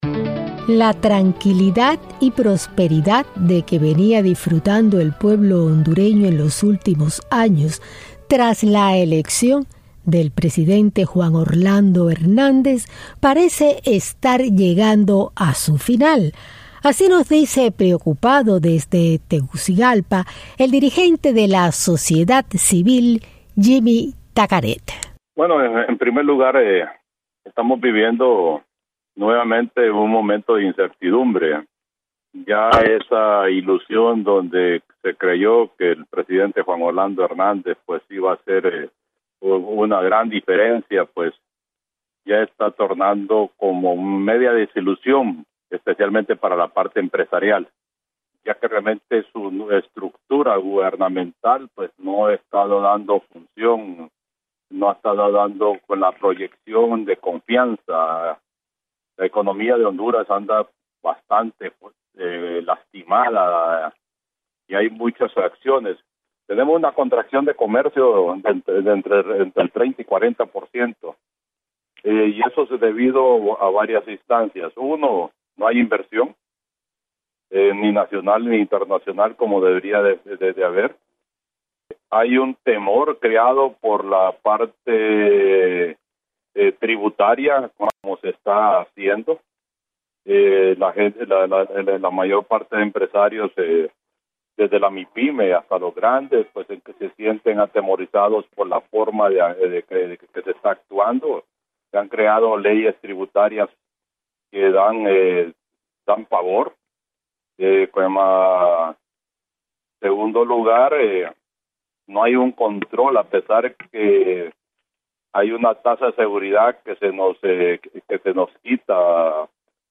Entrevistas en Honduras y Venezuela